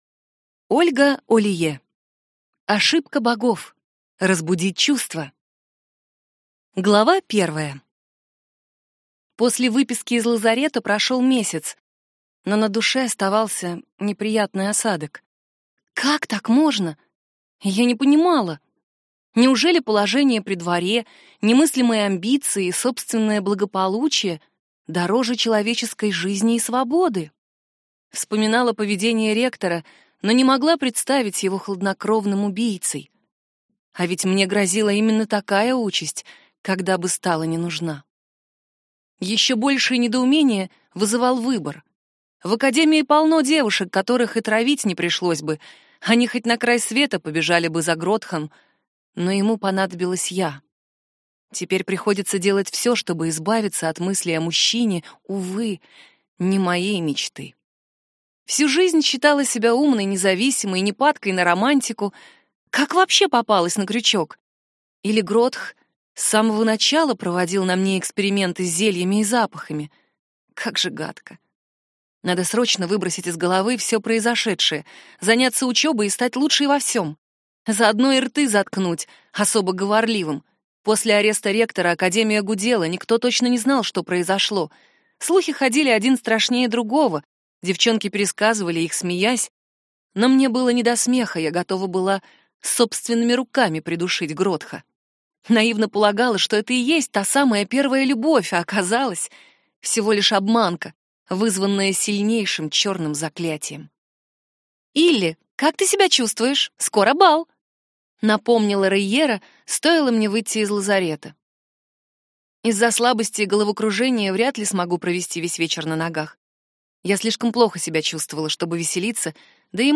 Аудиокнига Ошибка богов. Разбудить чувства | Библиотека аудиокниг